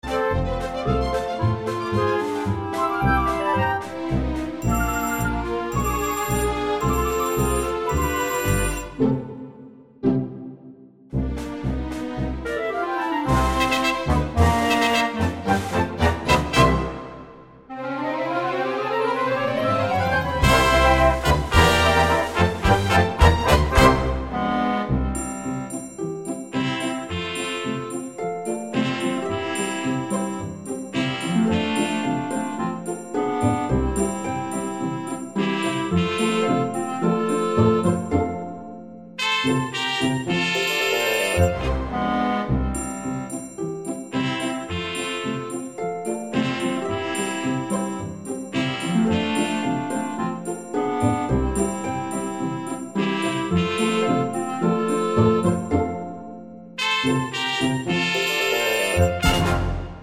no BV Soundtracks 4:12 Buy £1.50